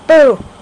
Boo! Sound Effect
Download a high-quality boo! sound effect.
boo-3.mp3